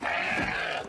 animalia_sheep_death.ogg